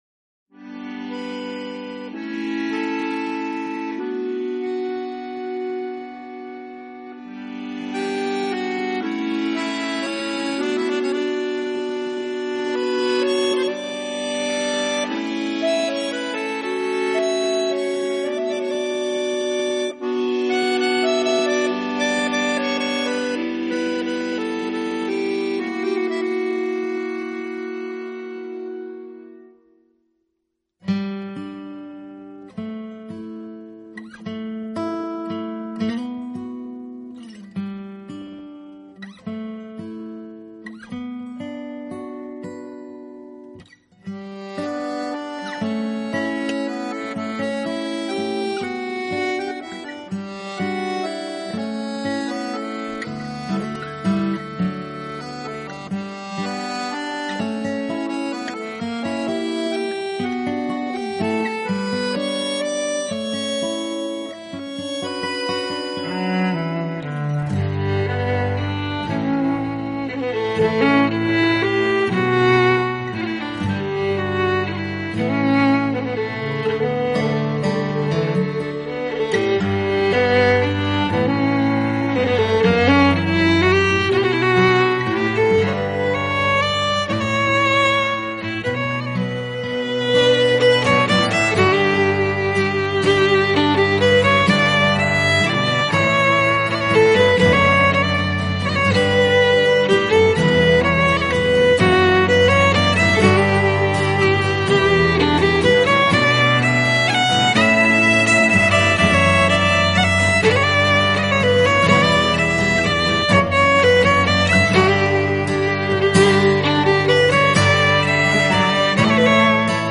小提琴专辑